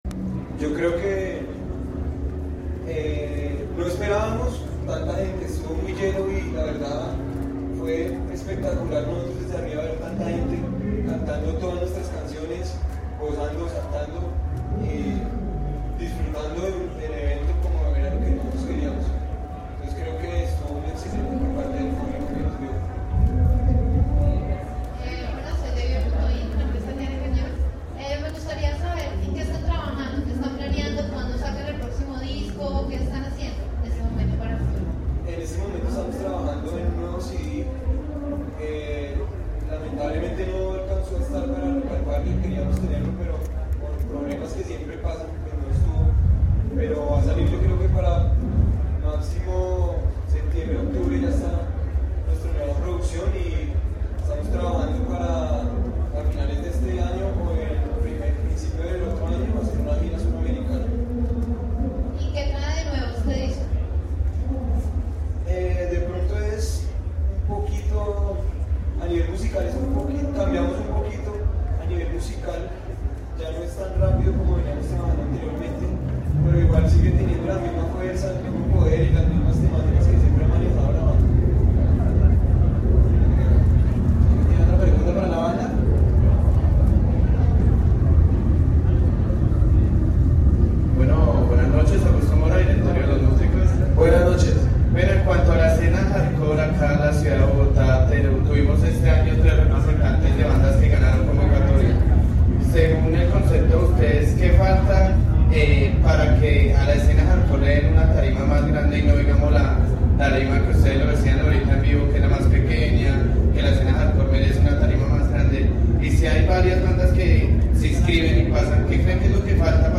Pitbull rueda de prensa